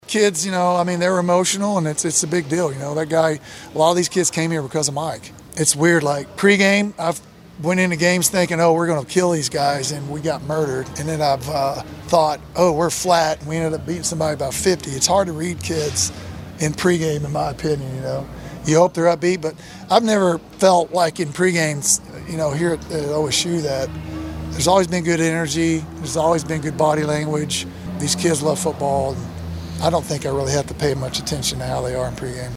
Oklahoma State interim head coach Doug Meacham talked with the media on Wednesday following practice in Stillwater.